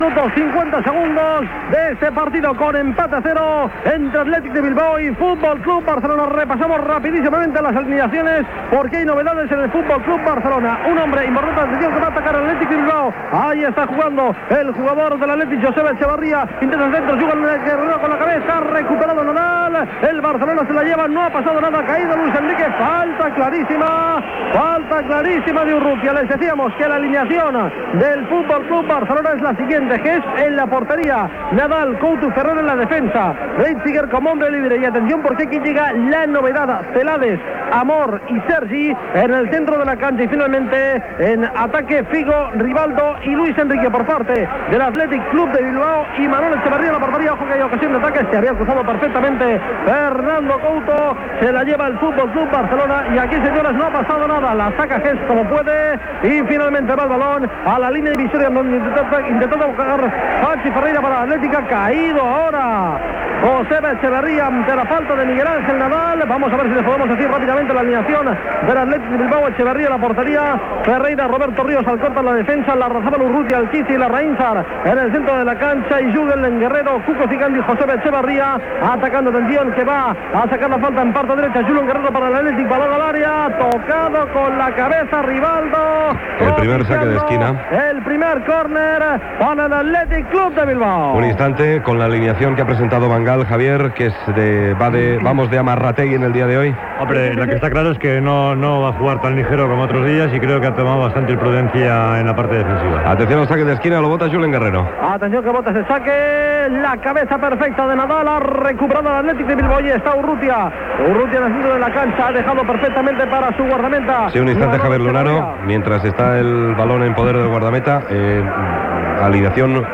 Transmissió del partit de la Llliga de Primera Diviisó de futbol masculí entre l'Atlético de Bilbao i el Futbol Club Barcelona. Aliniacions dels equips i narració de les jugades de la primera part amb valoracions tècniques i publicitat. Narració del gol de l'Atlético de Bilbao al final de la primera part, i valoració del partit i de la classificació de la Lliga al descans Gènere radiofònic Esportiu Anunciant Sasi